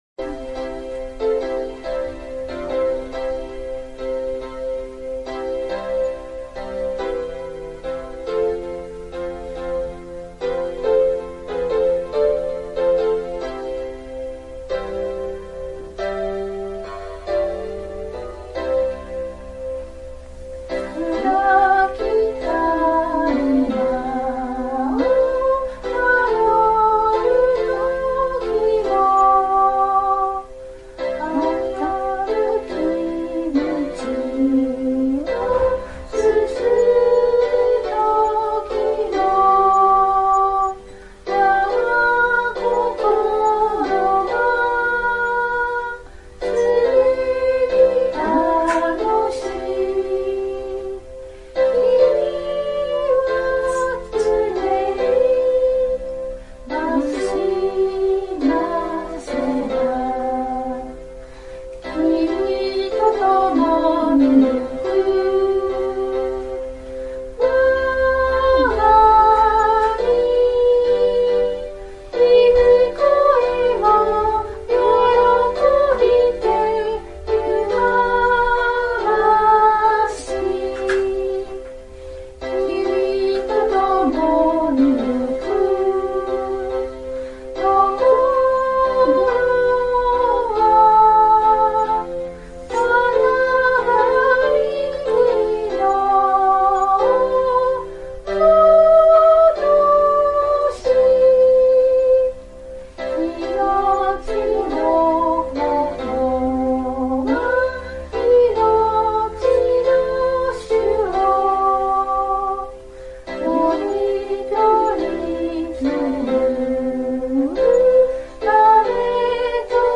唄